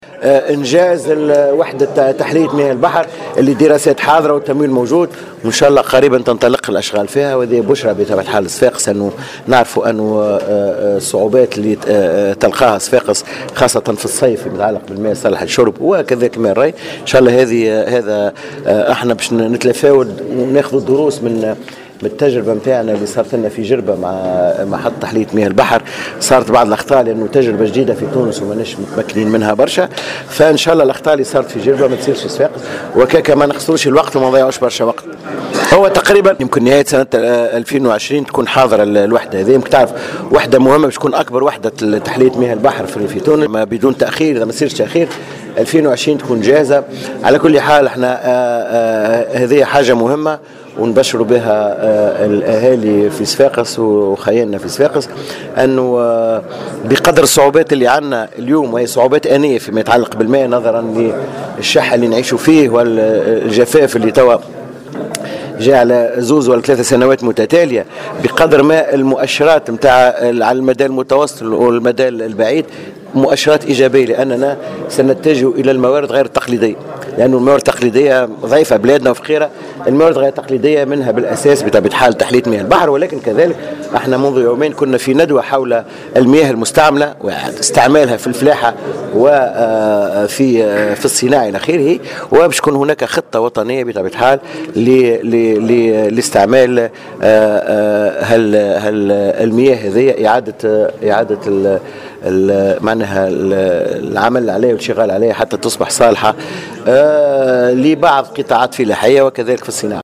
وأضاف الوزير في تصريح لمراسل الجوهرة اف ام خلال زيارته إلى صفاقس، أن هذه الوحدة التي تعد أكبر وحدة لتحلية مياه البحر في تونس ستكون جاهزة نهاية 2020، مضيفا أن هناك خطة وطنية لاستغلال المياه المستعملة حتى تصبح صالحة للقطاعات الفلاحية والصناعية.